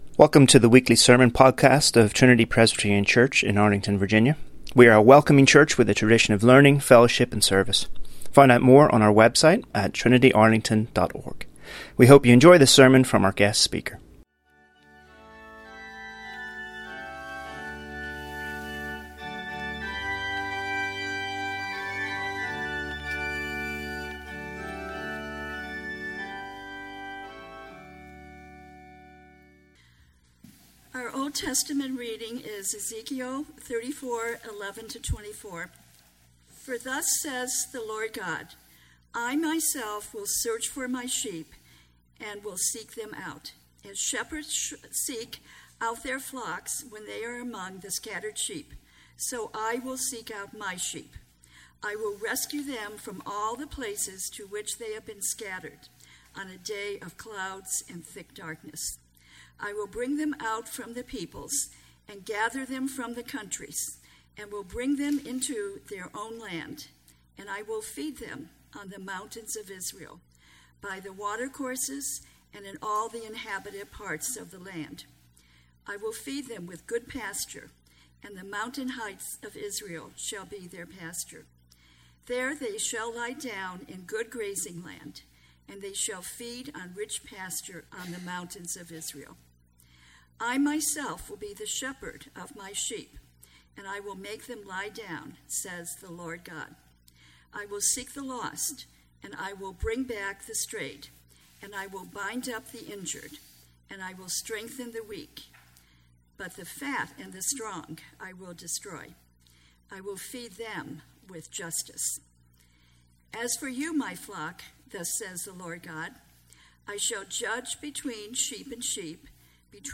Delivered Sunday, November 26, 2017 at Trinity Presbyterian Church, Arlington, Virginia.